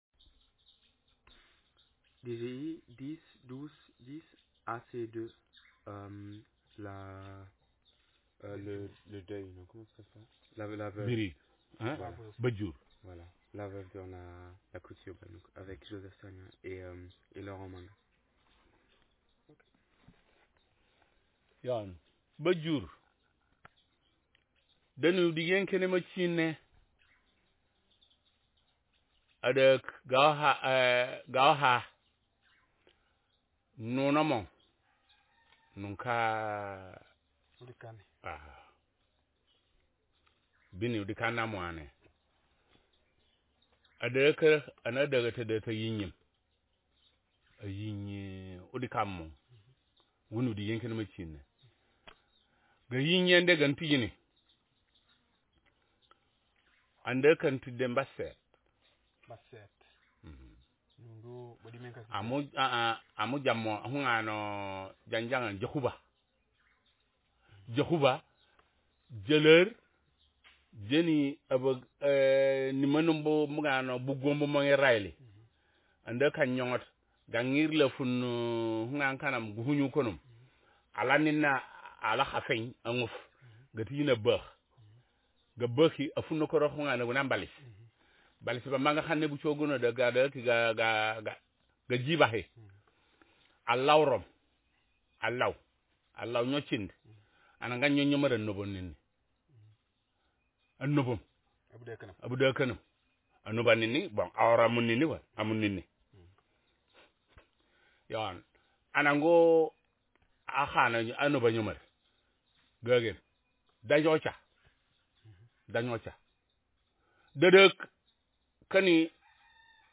Speaker sexm
Text genreprocedural